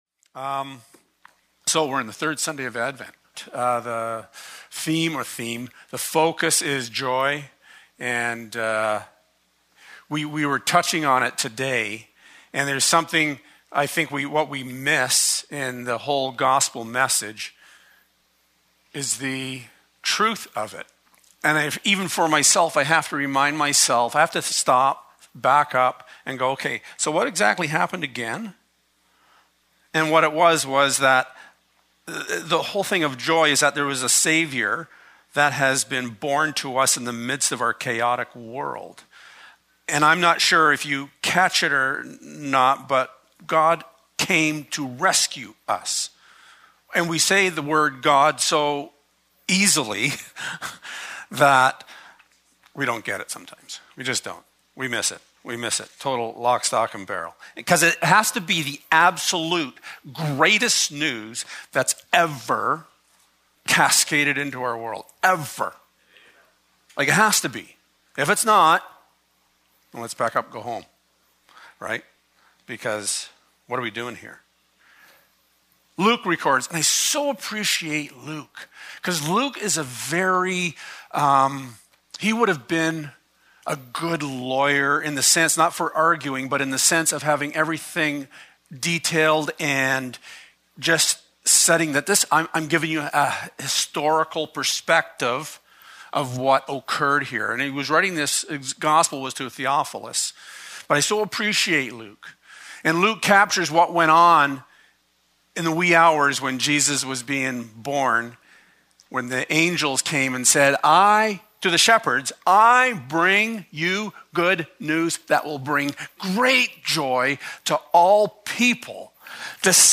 Passage: Romans 15: 13 Service Type: Sunday Morning Our focus for the third Sunday of Advent is joy.